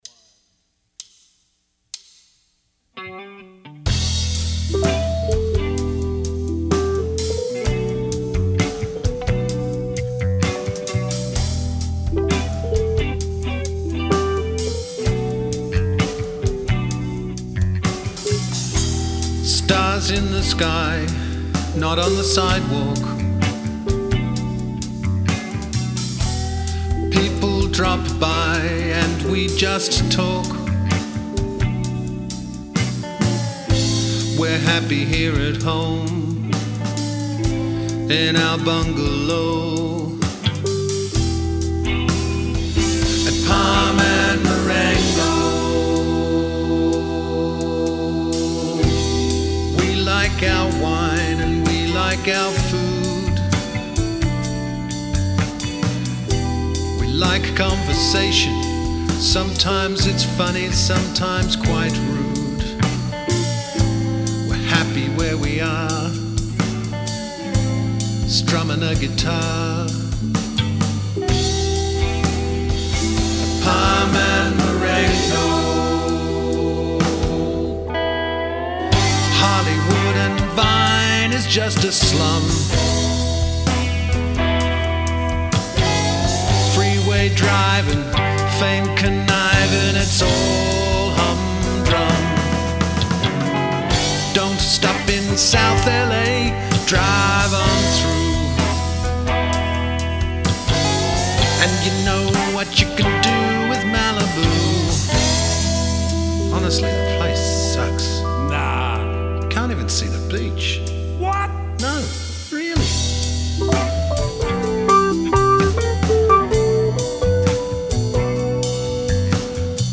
Drums
Guitar & Vocals
Bass
Keyboards
Slide Guitar